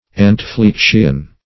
Search Result for " anteflexion" : The Collaborative International Dictionary of English v.0.48: anteflexion \an`te*flex"ion\ ([a^]n`t[-e]*fl[e^]k"sh[u^]n), n. (Med.)